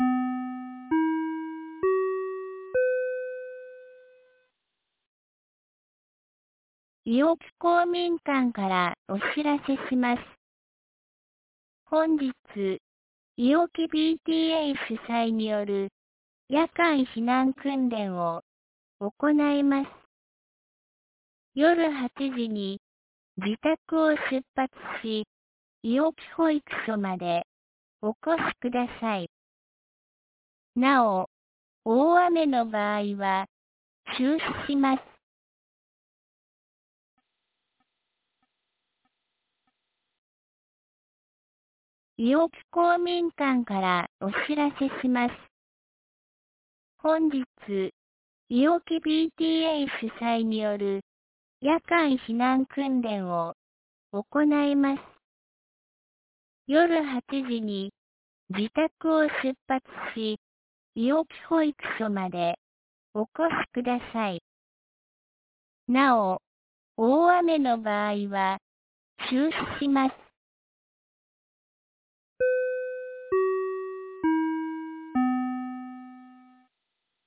2024年10月26日 17時11分に、安芸市より伊尾木、下山へ放送がありました。